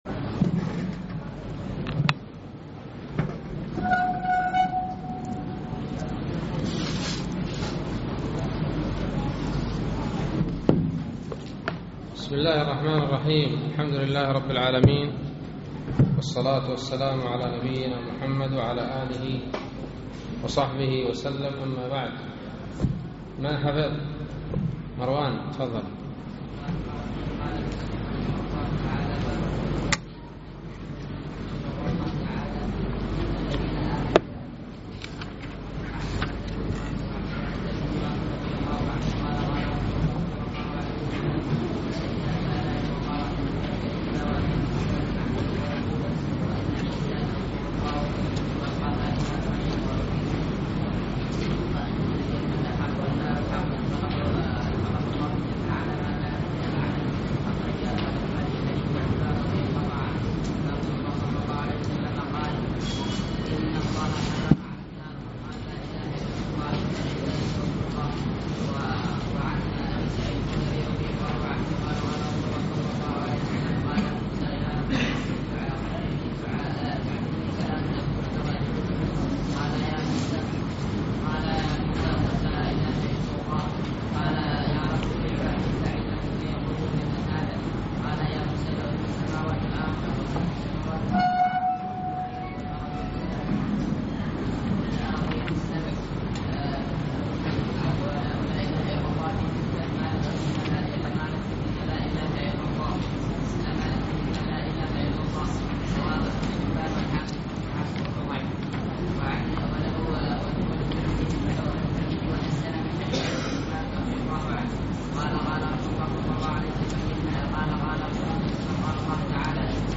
الدرس التاسع من شرح كتاب التوحيد